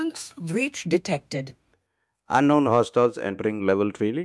novafarma/assets/audio 🔴/voiceover/prologue/prologue_06.wav